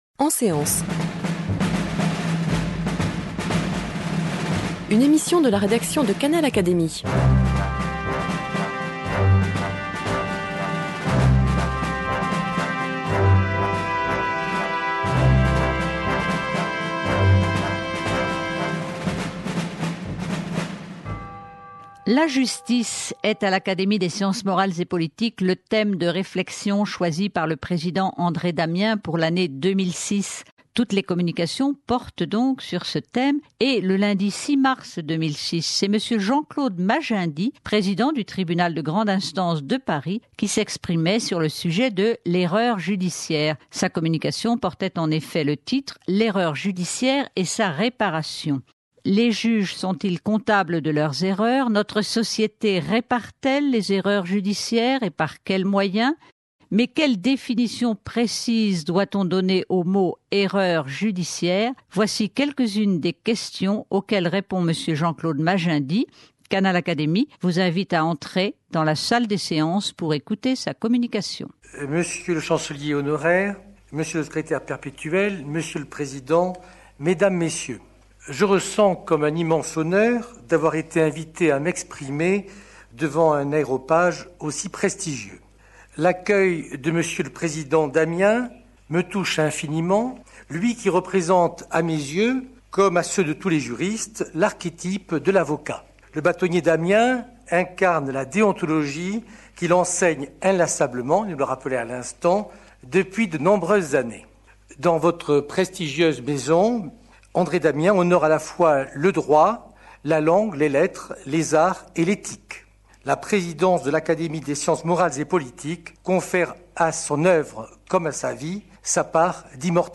Communication de Jean-Claude Magendie, Président du Tribunal de Grande Instance de Paris, prononcée en séance publique devant l’Académie des sciences morales et politiques lundi 6 mars 2006.